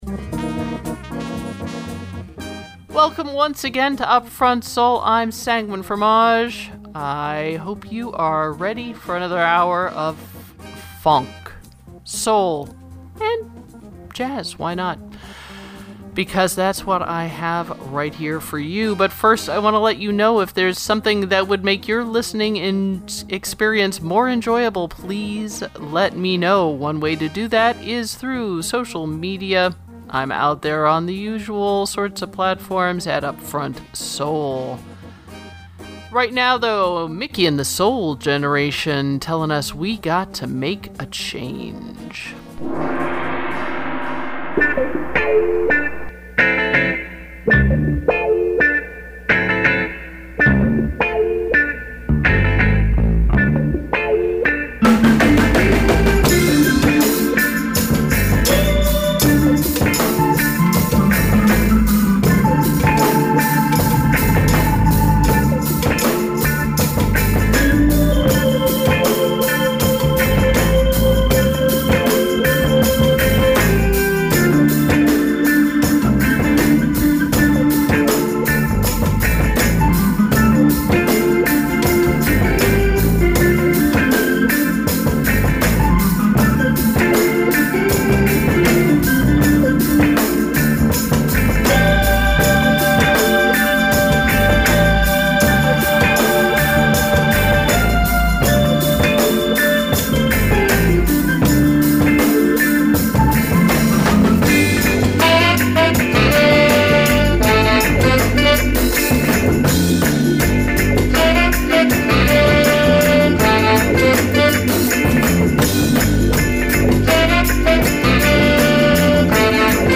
Funk, Soul & Jazz 120 Minutes of Soulful Sounds to Which You May Get Down